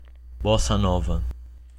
Bossa nova (Portuguese pronunciation: [ˈbɔsɐ ˈnɔvɐ]